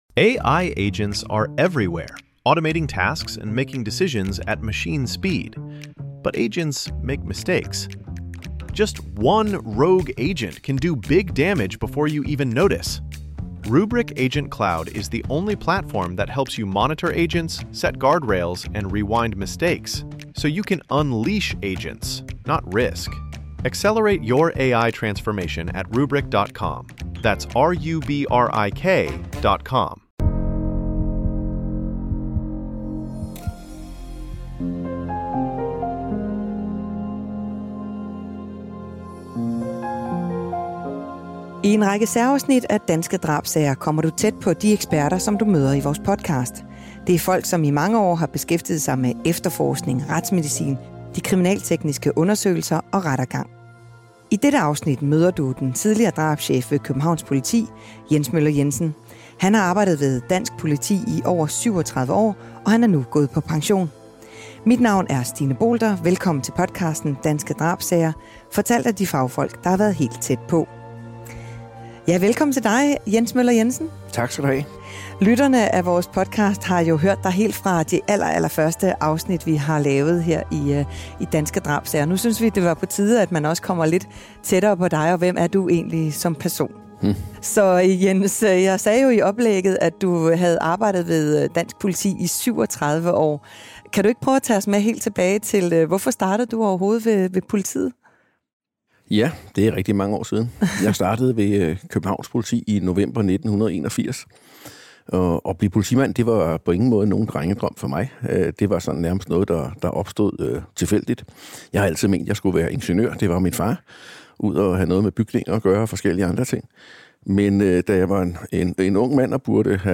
Medvirkende: Tidligere drabschef Jens Møller Jensen.